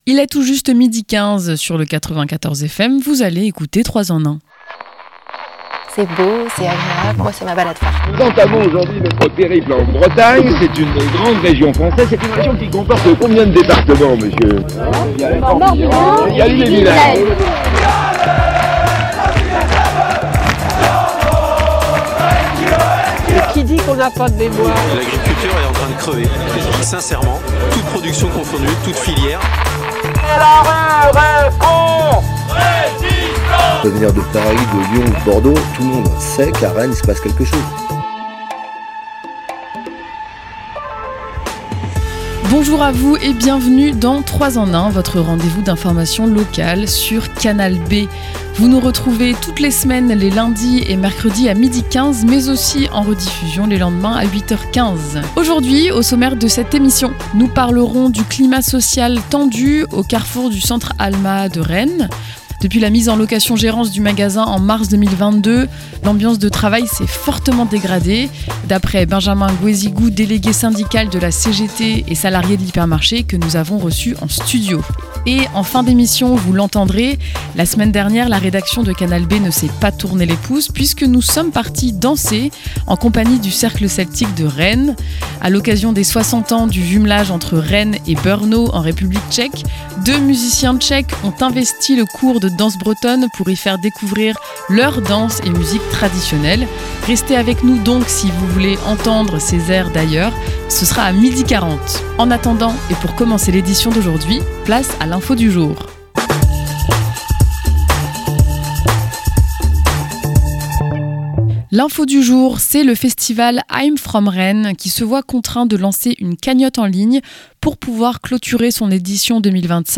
L'interview
Le reportage